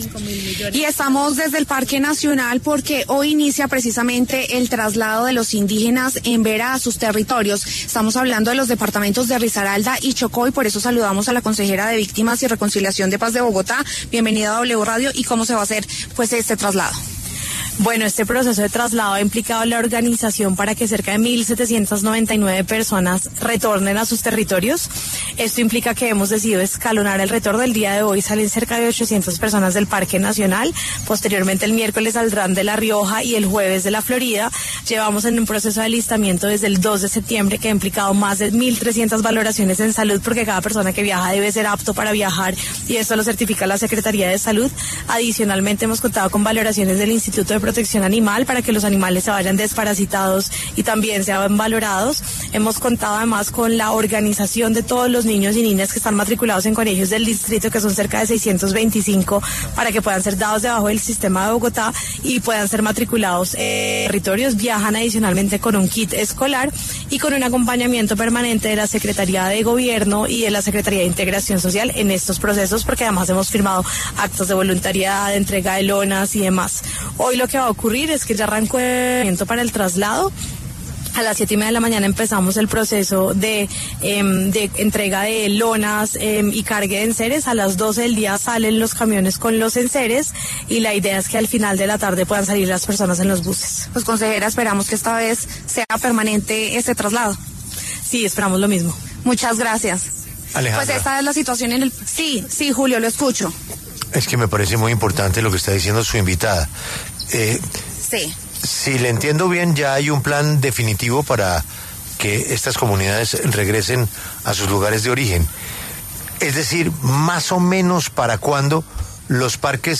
Para hablar sobre el tema, pasó por los micrófonos de La W la consejera de Paz, Víctimas y Reconciliación de Bogotá, Isabelita Mercado, quien explicó cómo será el proceso de retorno.